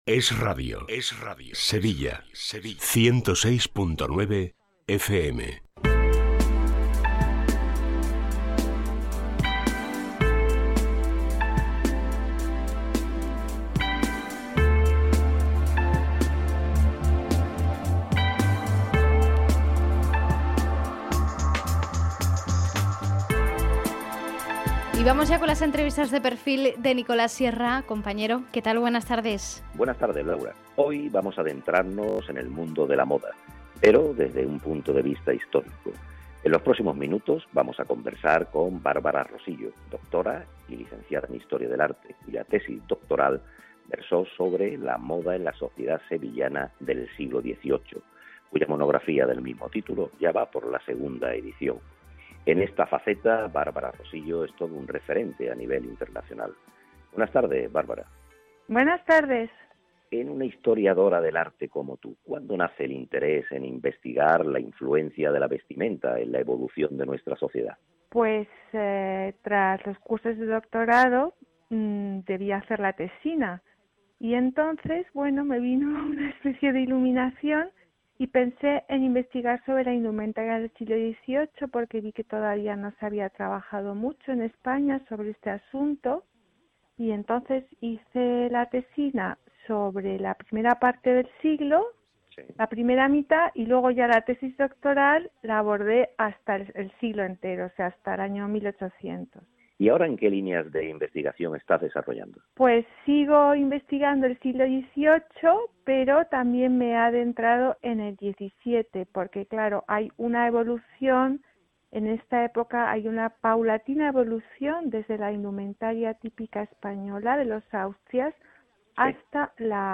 Entrevista en esRadio
Aquí os dejo la entrevista que me realizaron en el programa Es la mañana en la sección“Entrevista de Perfil” de esRadio Sevilla del día 30/03/2022.